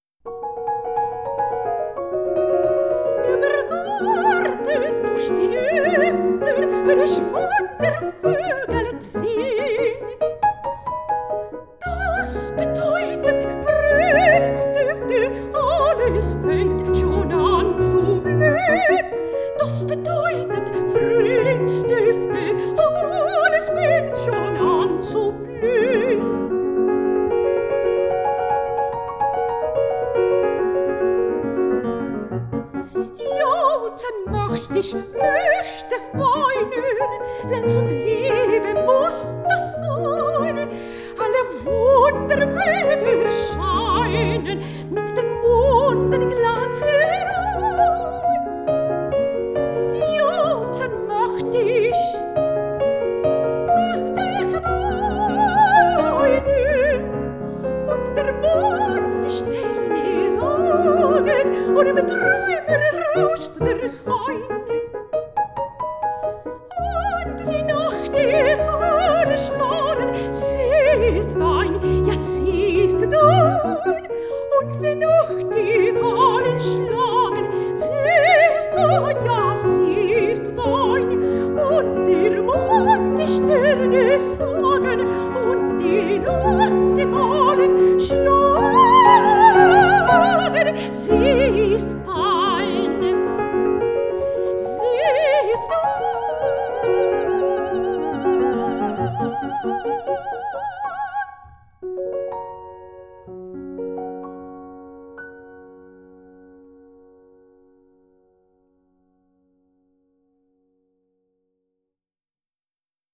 Mujeres y género: Clara Wiek y Fanny Mendelssohn, compilación de obras escritas para voz y piano
El objetivo fundamental de esta grabación es la difusión de obras de compositoras activas durante el periodo romántico de Alemania, desde una perspectiva de la interpretación históricamente informada.
Fanny Mendelssohn, Romanticismo Alemán, Género Lied